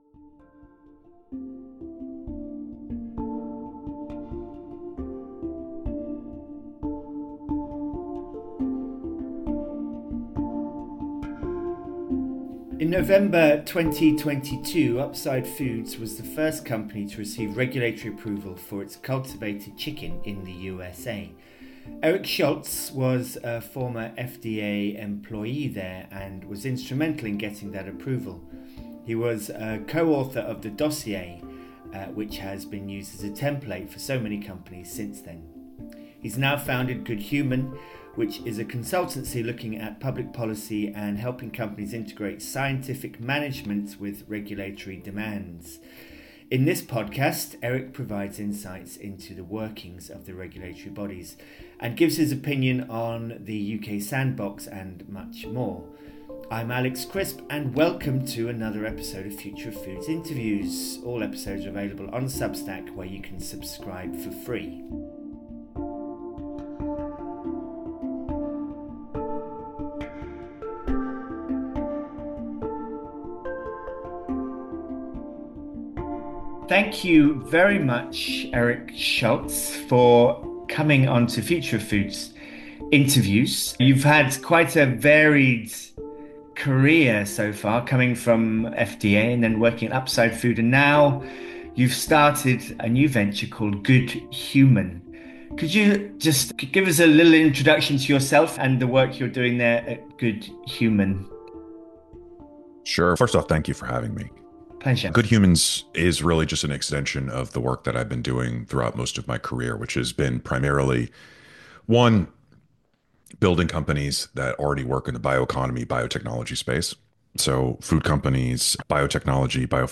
How AI 'virtual labs' are advancing alternative proteins - Triplebar – Future of Foods Interviews - Alt Proteins, Cell Agriculture, an End to Factory Farming.